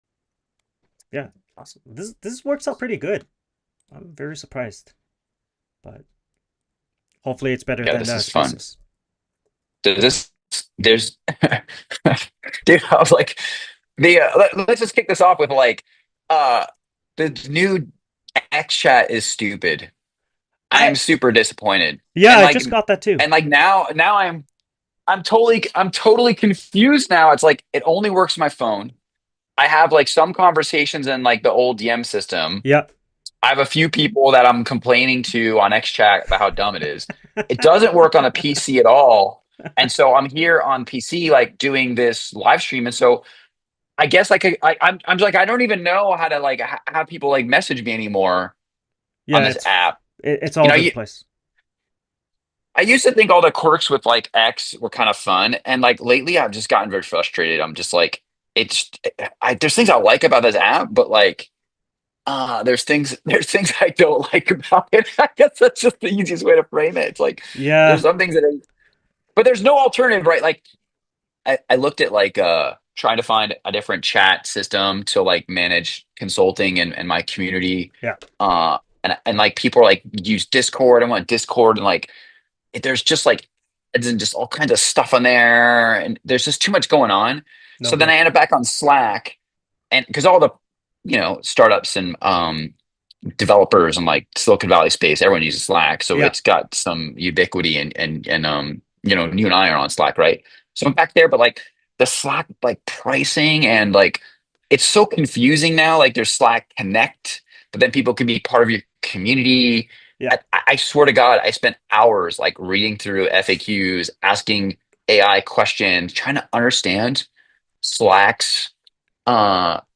Expect smart takes, dumb takes, a few hot ones, and more than a few memes. The show streams live on X and LinkedIn.